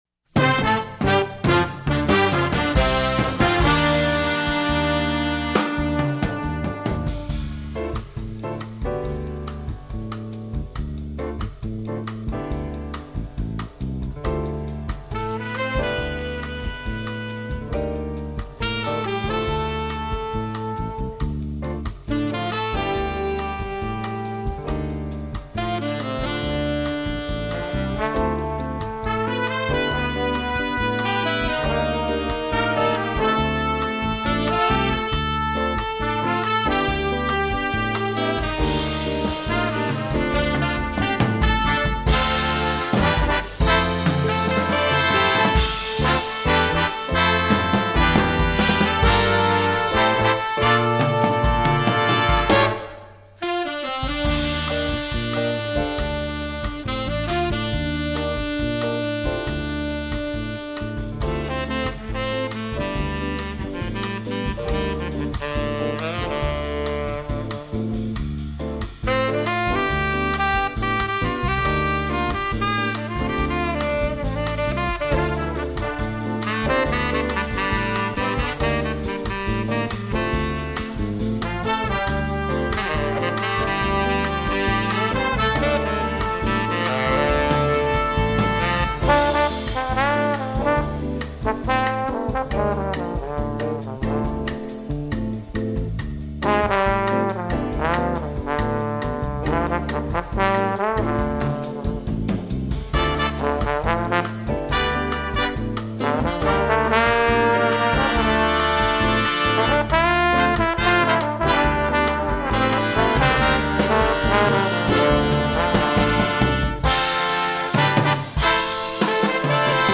jazz, rock